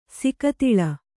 ♪ sikatiḷa